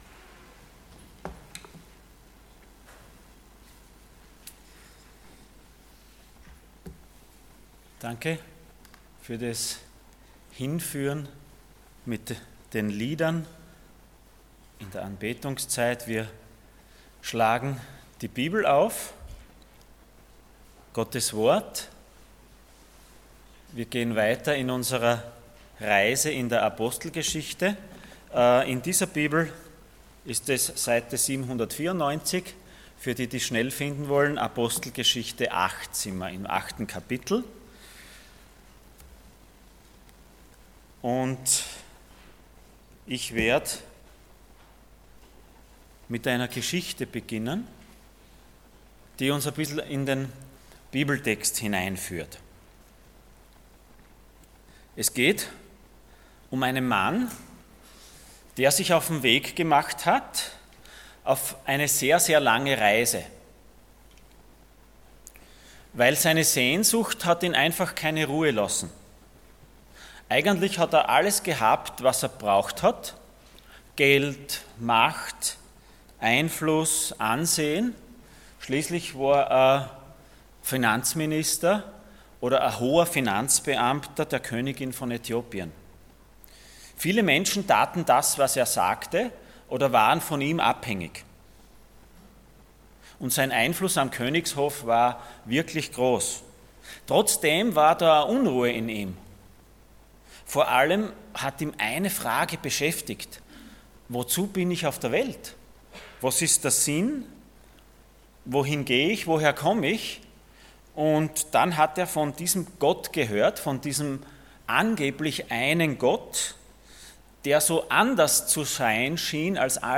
Acts 8:26-40 Dienstart: Sonntag Morgen %todo_render% Verstehst du